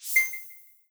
Success11.wav